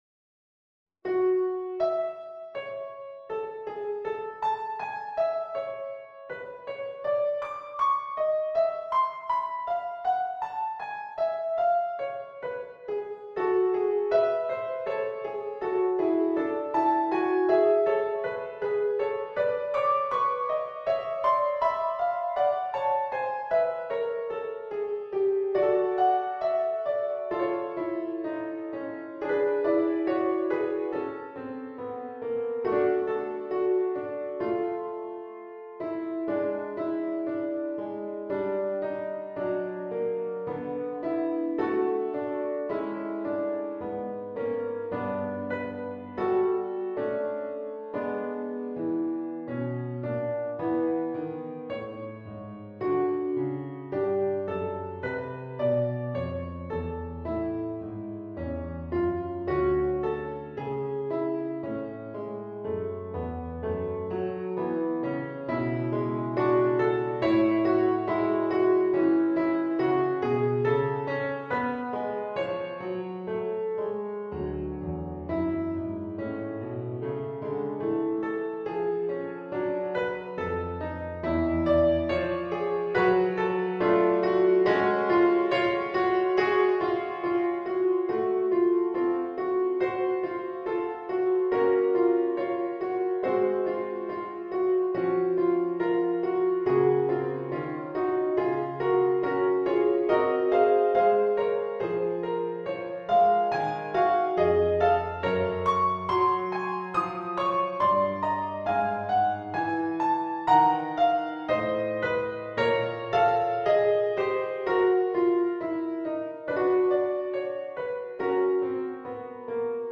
in Piano Music, Solo Keyboard
Here is the latest installment of preludes, no.16. This one deals with a lot of two voice counterpoint and a relentless ramble on one theme.
P.S. some of those tempo markings are to mimic rubato, dunno I suck as an editor